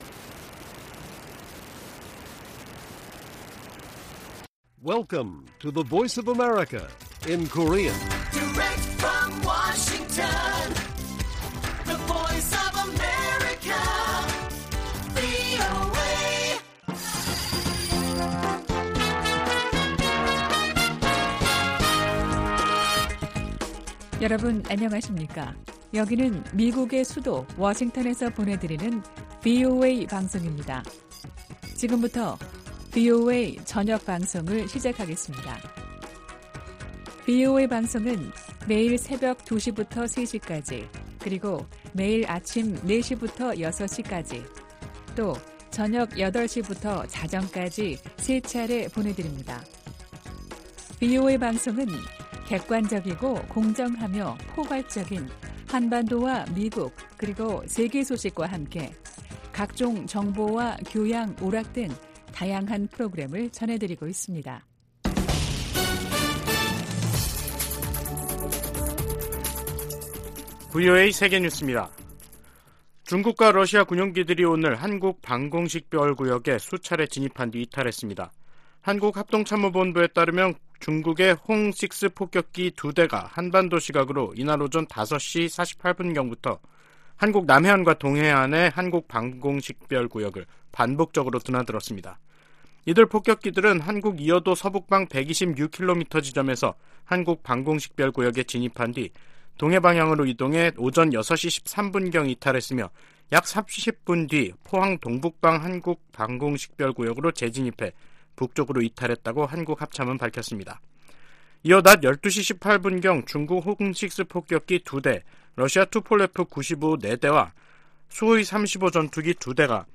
VOA 한국어 간판 뉴스 프로그램 '뉴스 투데이', 2022년 11월 30일 1부 방송입니다. 북한 정권이 7차 핵 실험을 강행하면 대가가 따를 것이라고 미국 국방부가 거듭 경고했습니다. 중국의 핵탄두 보유고가 2년여 만에 2배인 400개를 넘어섰으며 2035년에는 1천 500개에 이를 것이라고 미국 국방부가 밝혔습니다.